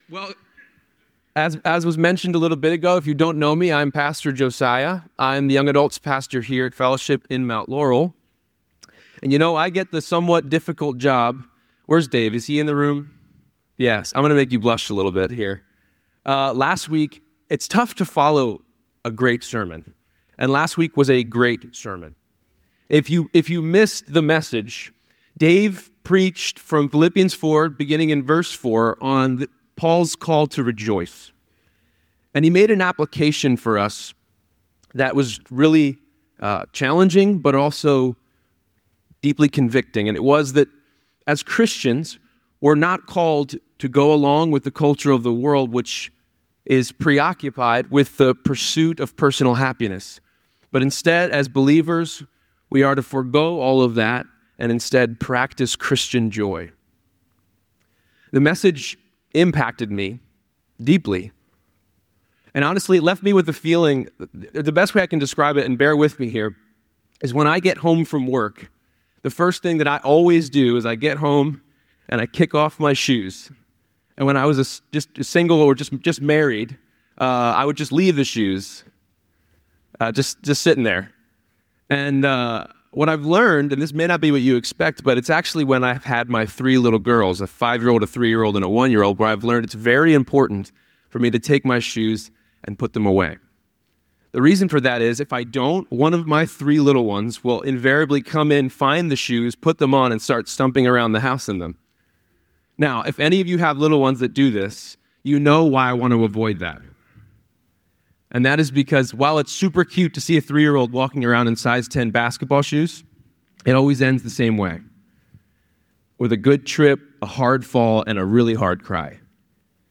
The sermon concludes with a prayer and a call to embrace God's peace through continuous prayer and trust.